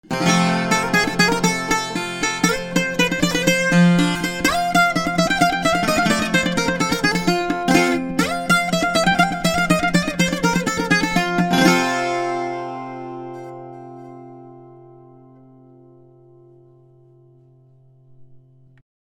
modern - conventional 8 strings bouzouki made by maple and walnut with a new wave desing and with the following specifications:
• Speaker wood: maple - walnut